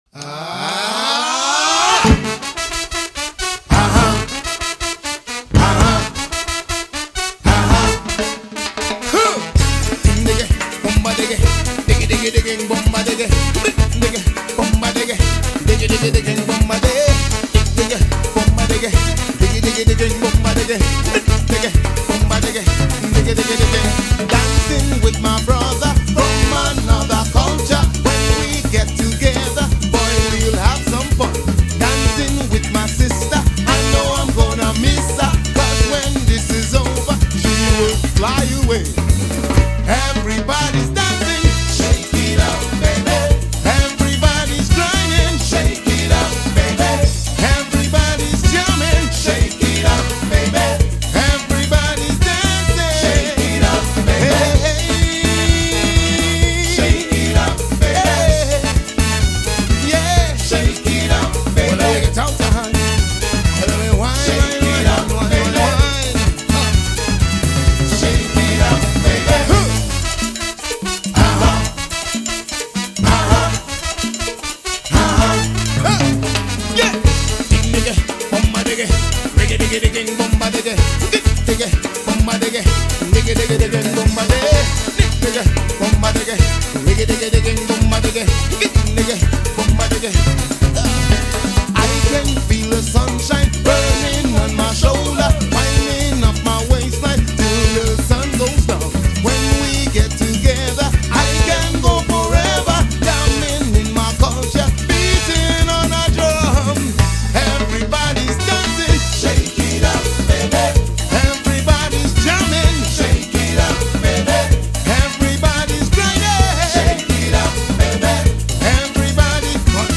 steel pans/bass/background vocals